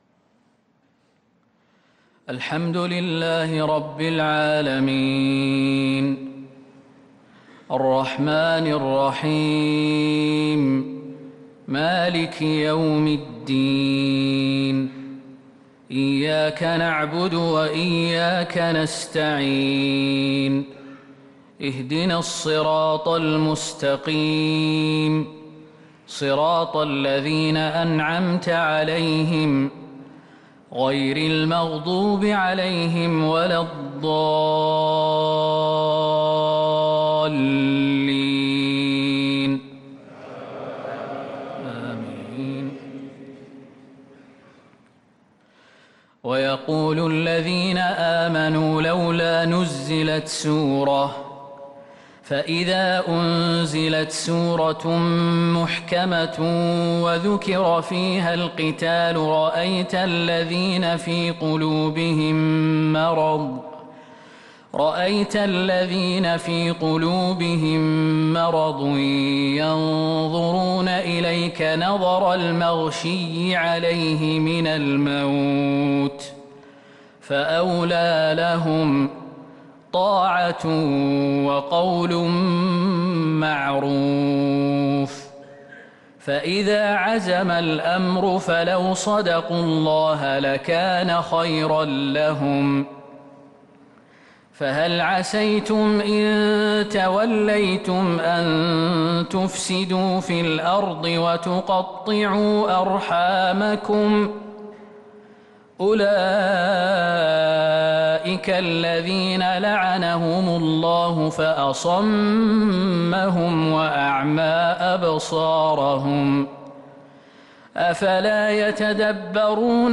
صلاة الفجر للقارئ خالد المهنا 14 ذو القعدة 1443 هـ
تِلَاوَات الْحَرَمَيْن .